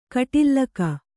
♪ kaṭillaka